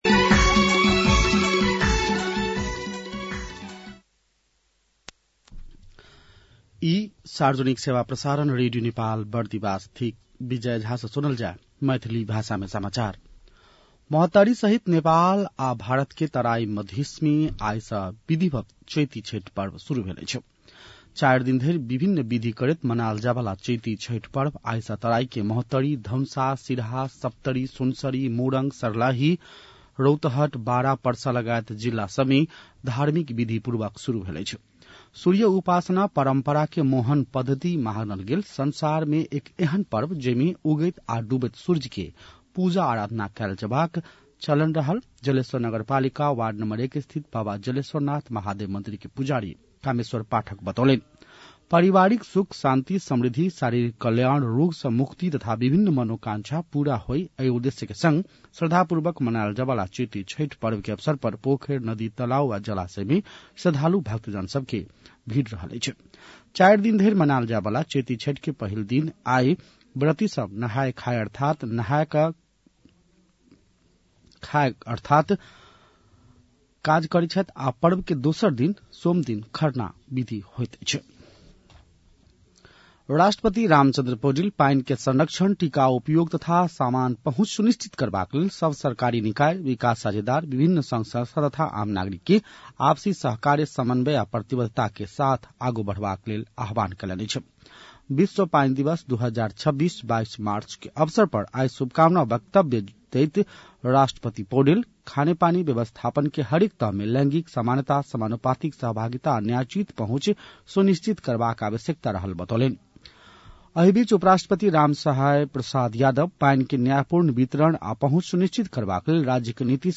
मैथिली भाषामा समाचार : ८ चैत , २०८२
6.-pm-MAITHALI-news-.mp3